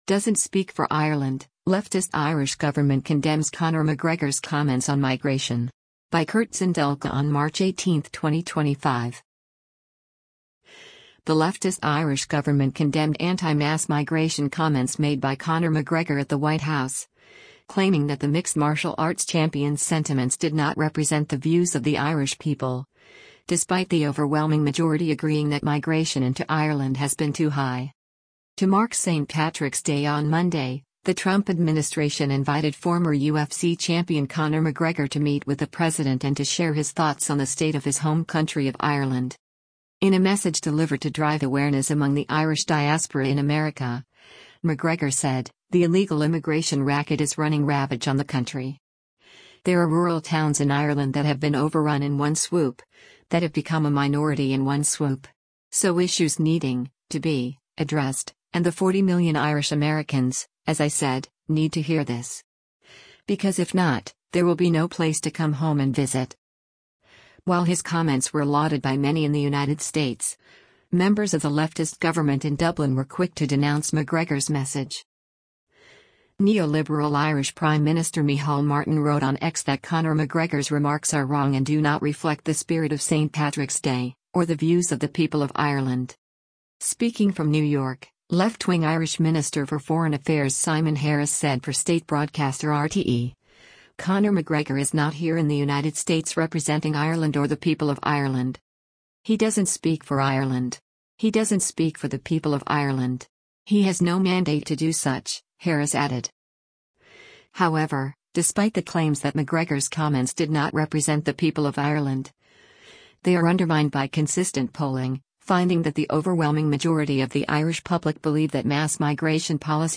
UFC fighter Conor McGregor speaks in the James S. Brady Press Briefing Room of the White H